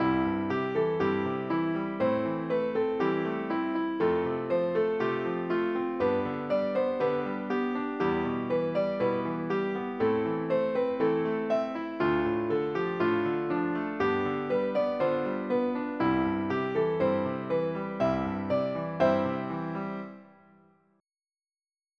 Block chords added to accompany melody
Result: Block chord accompaniment with smooth voice leading on a new Chords track
piano_add_chords.wav